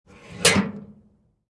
mailbox_close_2.mp3